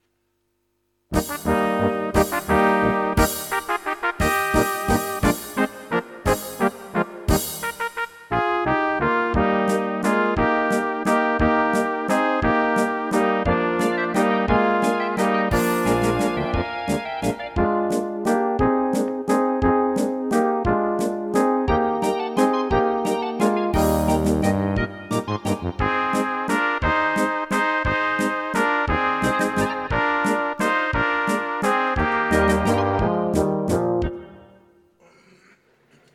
Rubrika: Národní, lidové, dechovka
- valčík
Karaoke